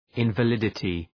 Προφορά
{,ınvə’lıdətı}